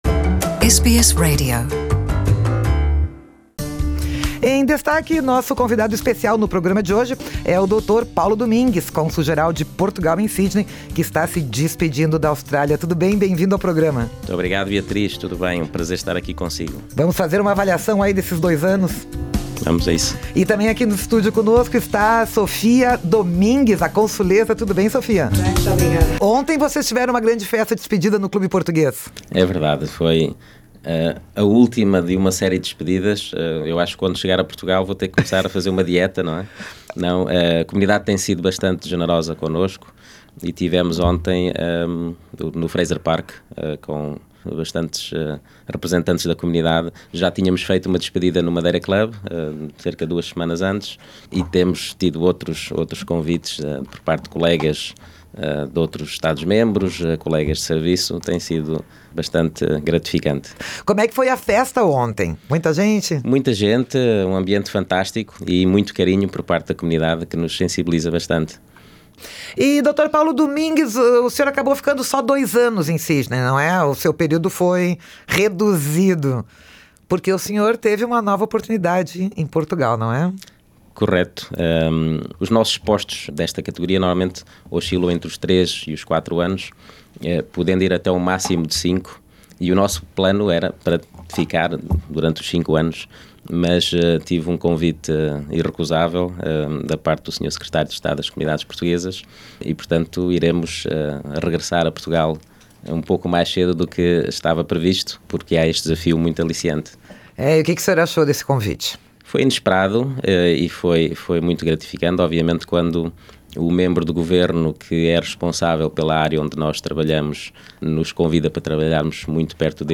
Ele recebeu uma proposta de trabalho "excelente" em Lisboa, como chefe de gabinete do Secretário de Estado das Comunidades Portuguesas. Acompanhe aqui o podcast com a íntegra da entrevista.
Em entrevista ao Programa Português da Rádio SBS na semana da despedida, o Cônsul-Geral Paulo Domingues fez um balanço bastante positivo dos seus dois anos à frente do consulado.